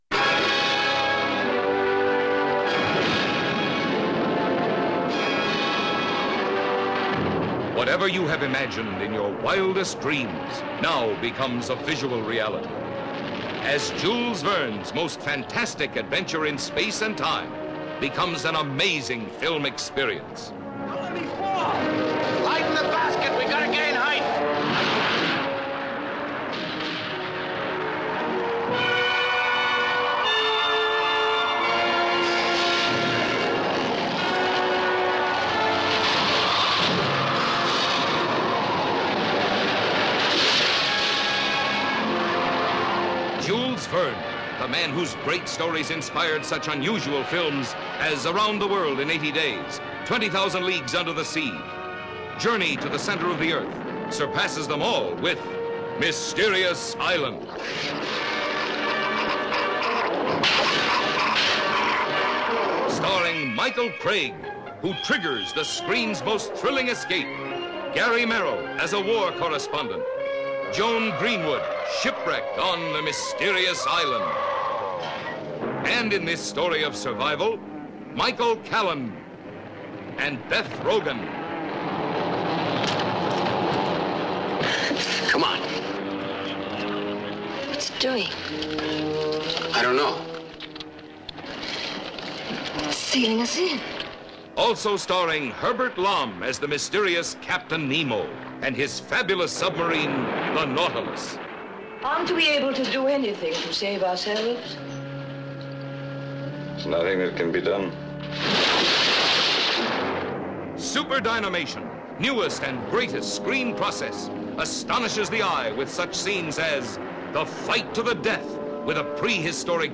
Trailer Audio: Mysterious Island (1961)
mysterious-island-61-trailer.mp3